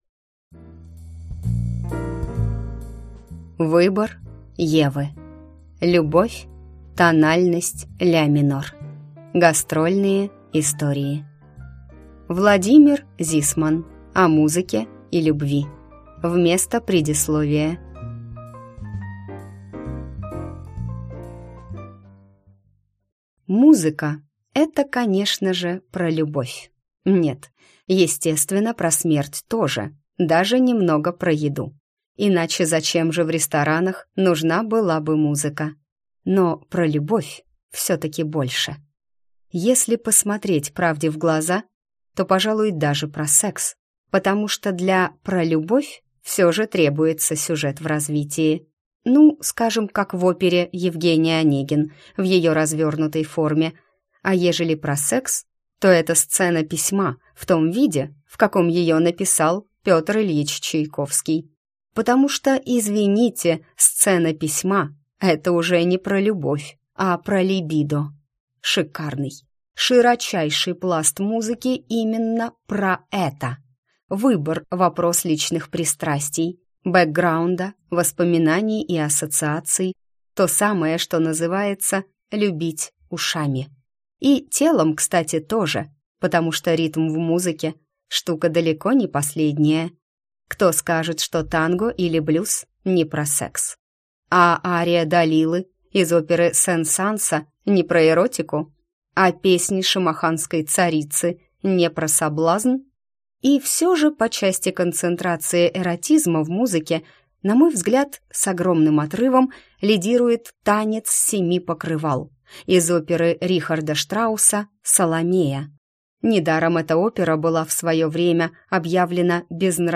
Аудиокнига Выбор Евы. Гастрольные истории. Любовь – тональность ля минор | Библиотека аудиокниг